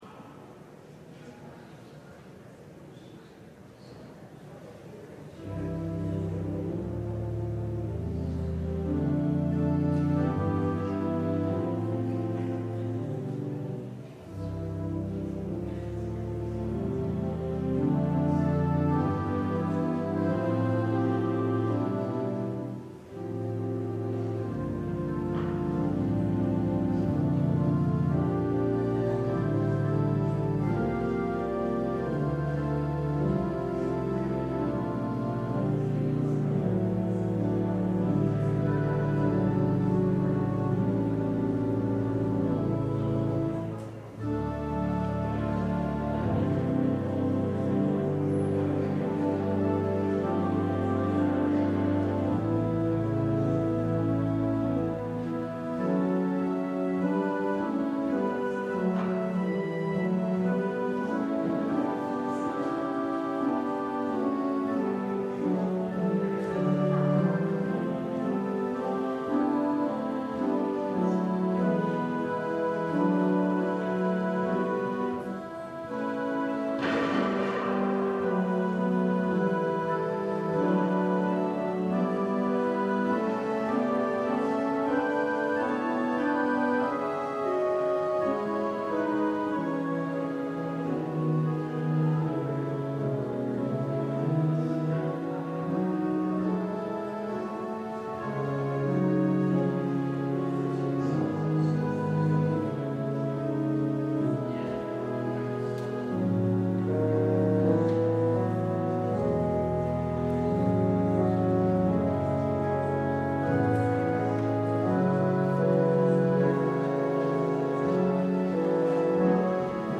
LIVE Evening SPECIAL Worship Service - New Year's Eve Candlelight Service